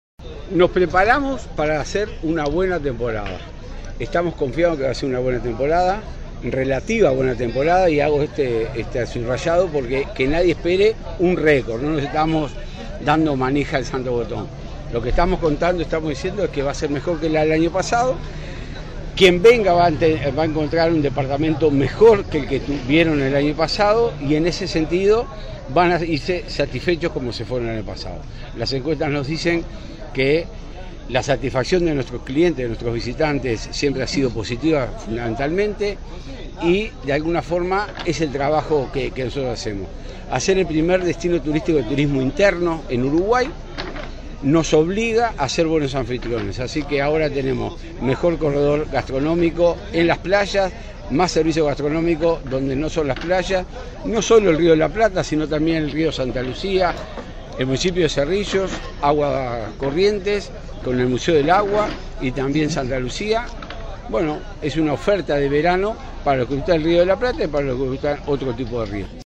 Horacio Yanes, director de Turismo de la Intendencia de Canelones, destacó en conferencia de prensa: “Estamos confiados en que esta temporada será mejor que la del año pasado. Nadie debe esperar un récord, pero quien nos visite encontrará un departamento mejor preparado y con un alto nivel de satisfacción garantizado, según lo indican nuestras encuestas”.